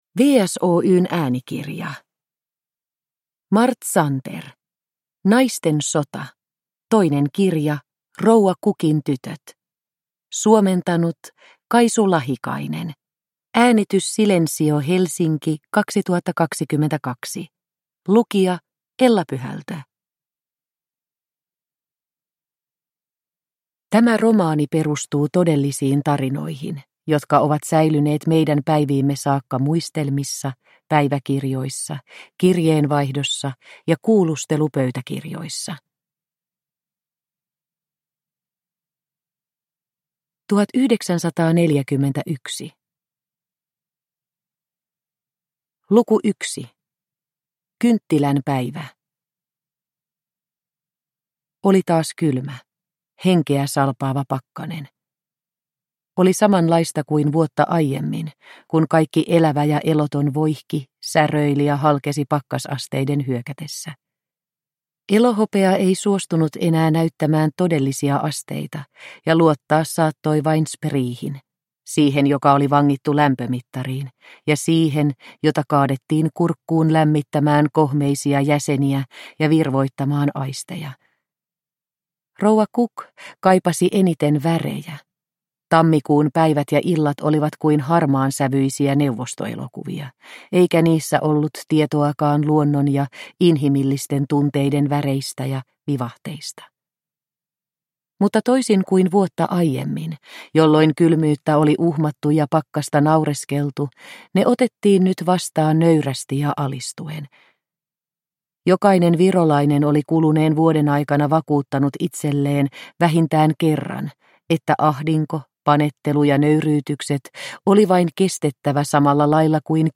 Naisten sota – Ljudbok – Laddas ner